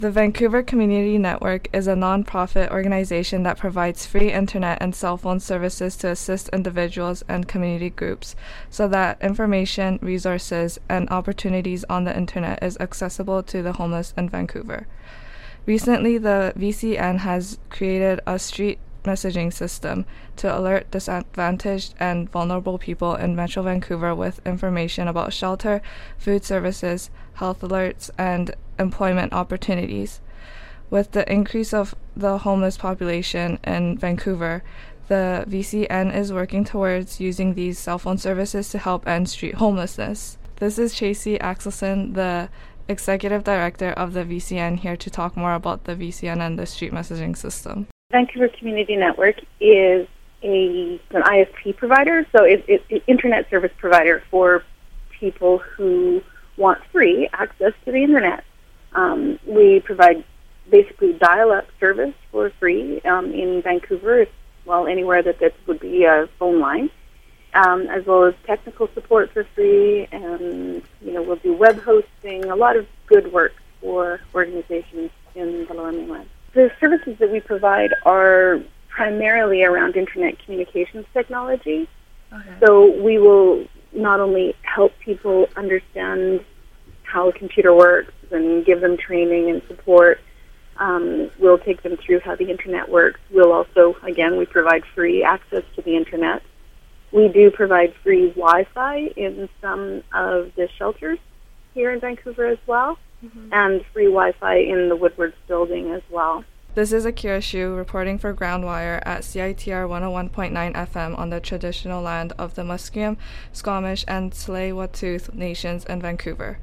Recording Location: Vancouver, British Columbia
Type: News Reports